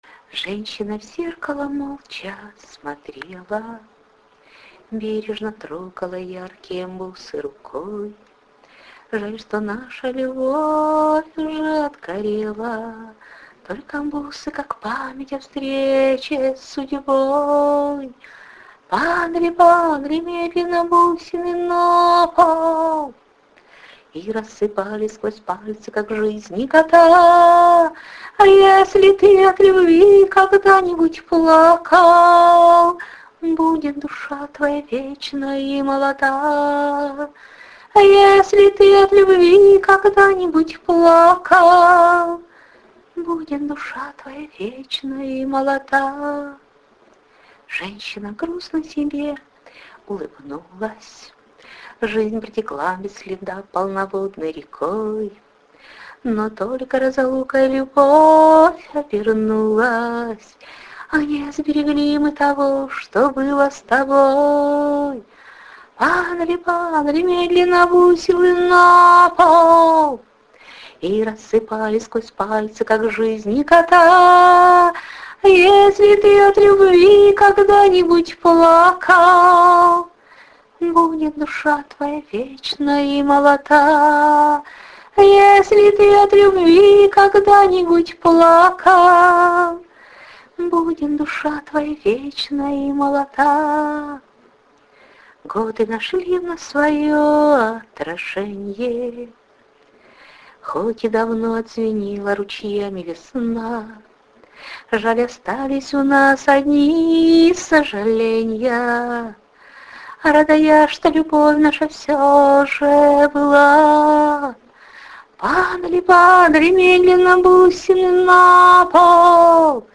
Рубрика: Поезія, Авторська пісня
Замечательный стих проникновенно спетый. hi love03 love03